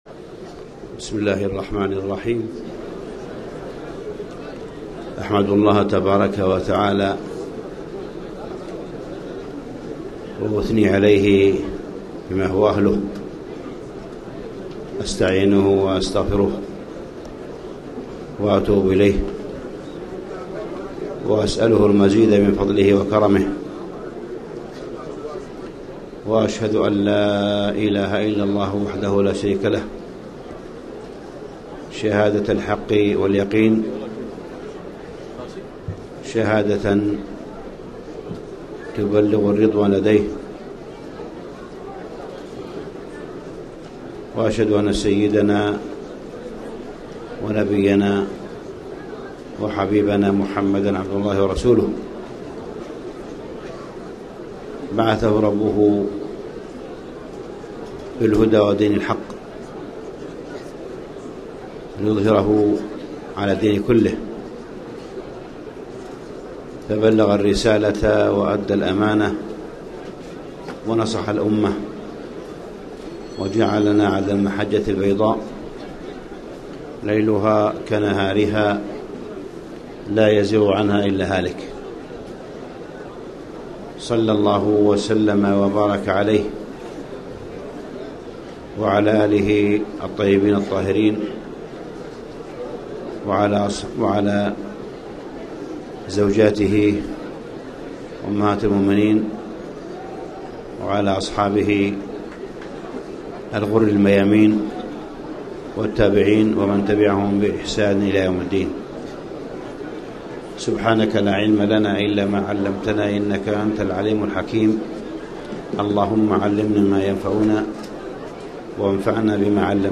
تاريخ النشر ٥ ذو الحجة ١٤٣٨ هـ المكان: المسجد الحرام الشيخ: معالي الشيخ أ.د. صالح بن عبدالله بن حميد معالي الشيخ أ.د. صالح بن عبدالله بن حميد حجة الوداع